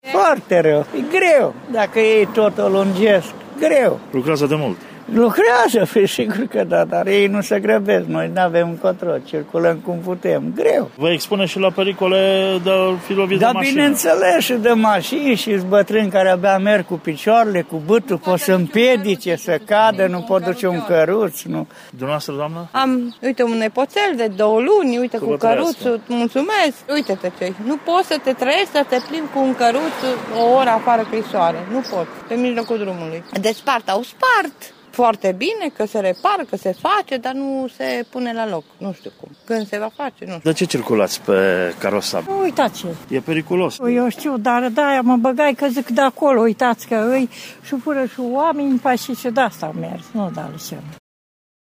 Acum trotuarul este spart şi cei care circulă, merg alături de maşini. Ascultaţi opinia câtorva locuitori din Moroasa:
Vox-Moroasa.mp3